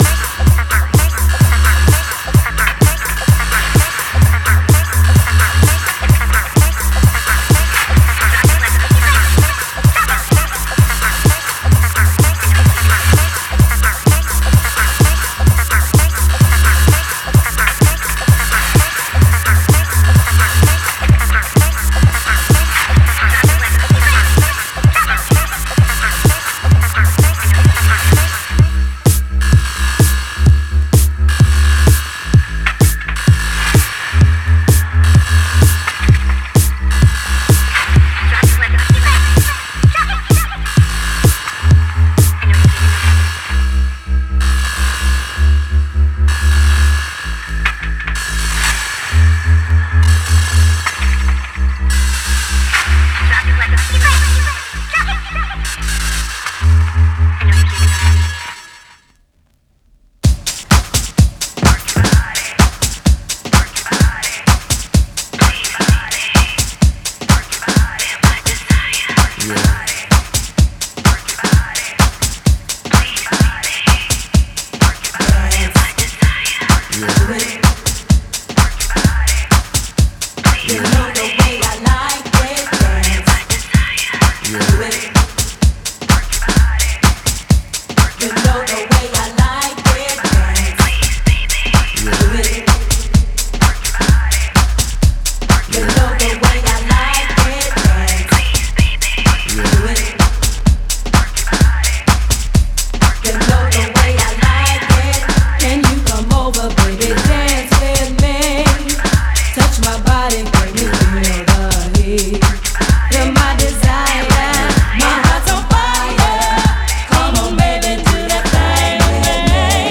狂おしくも可愛らしい謎な感性のハウス、キャッチーなディスコ、R&B、ガラージネタなどを取り混ぜ